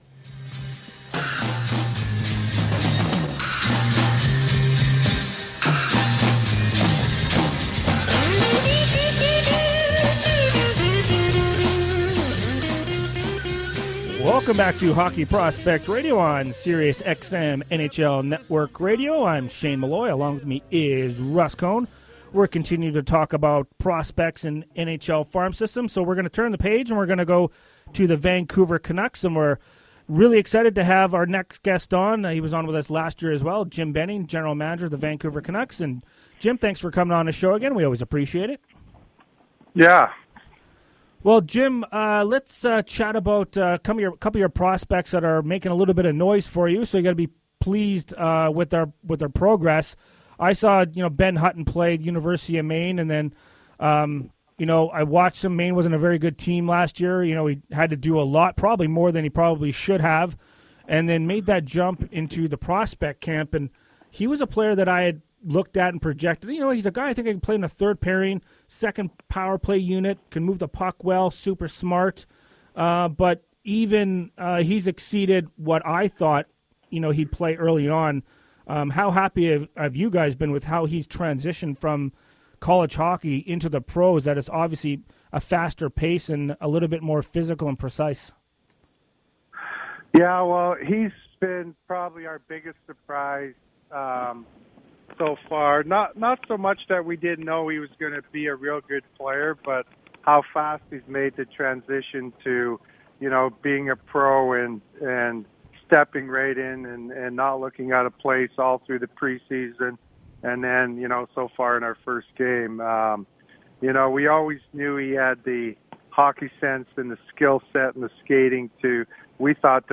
The interview includes discussion on rookies Ben Hutton, Jared McCann and their 1st rounder in the 2015 NHL Draft, Brock Boeser.